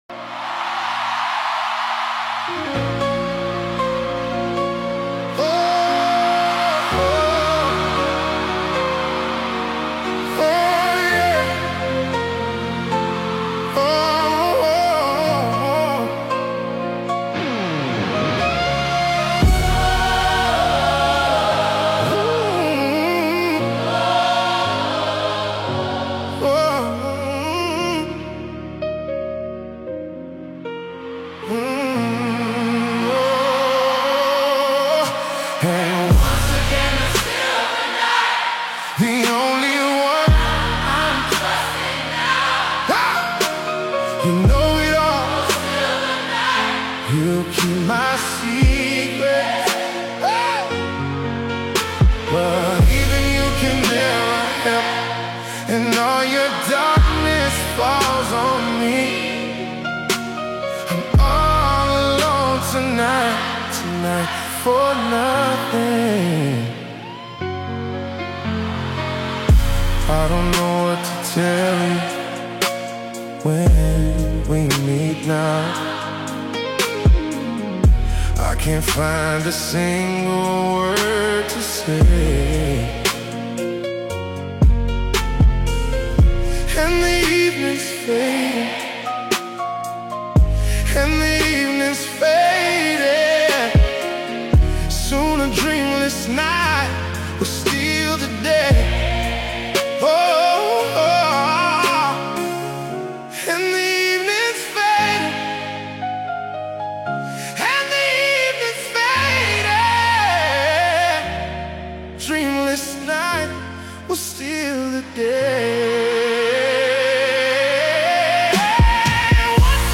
AI Cover